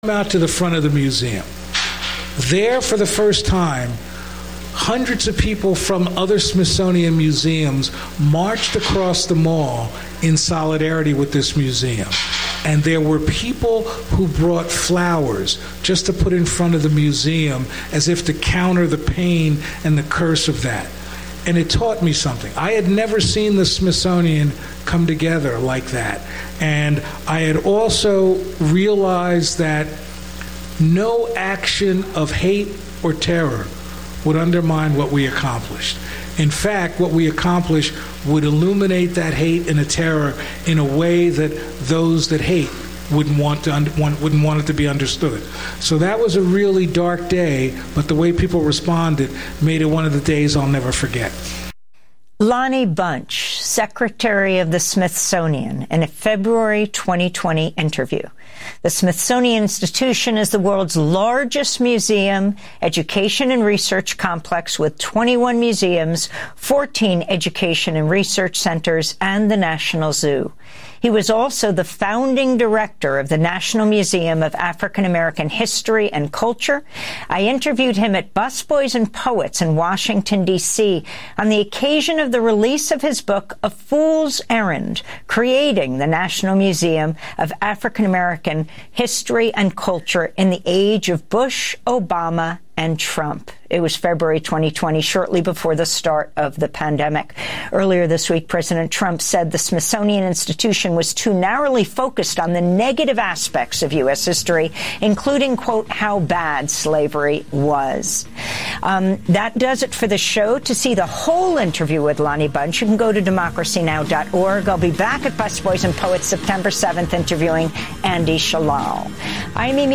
Evening News on 08/21/25